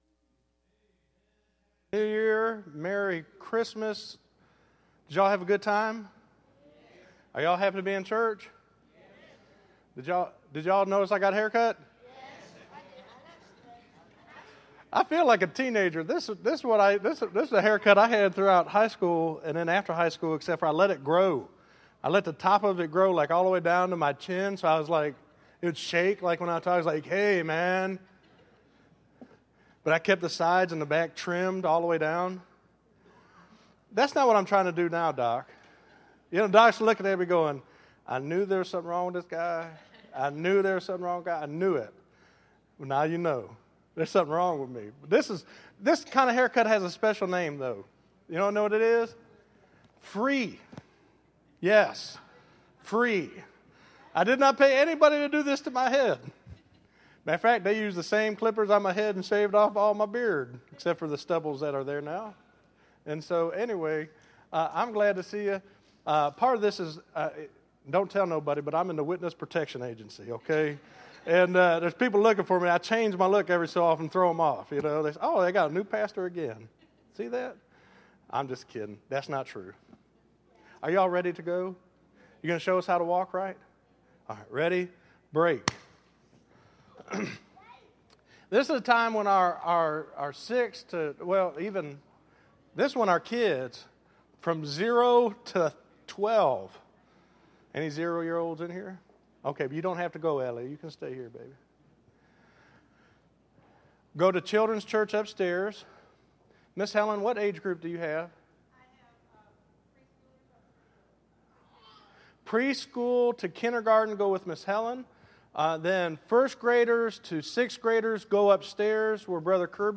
Listen to Finding Jesus In the Word Daily - 01_05_14_Sermon.mp3